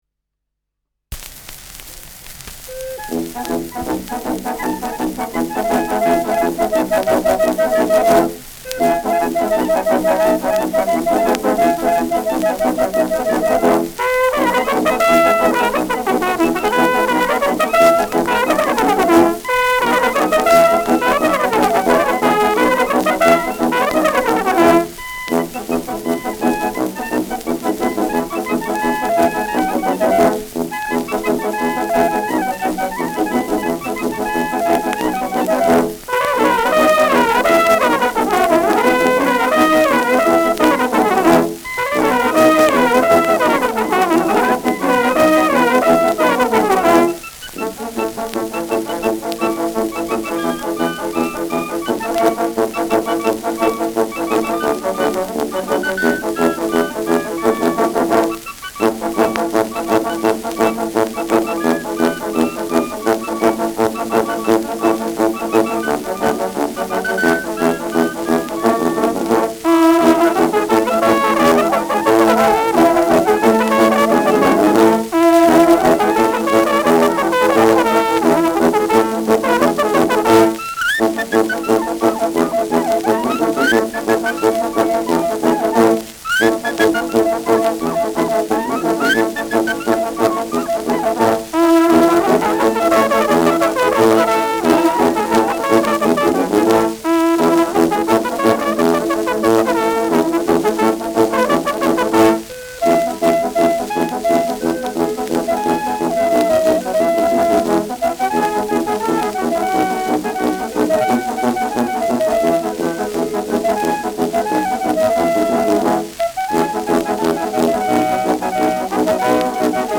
Schellackplatte
Tonrille: Kratzer 12 Uhr : Abrieb durchgängig
leichtes Rauschen
Stadtkapelle Gunzenhausen (Interpretation)
Mit Juchzern. Enthält bekannte Vierzeilermelodien.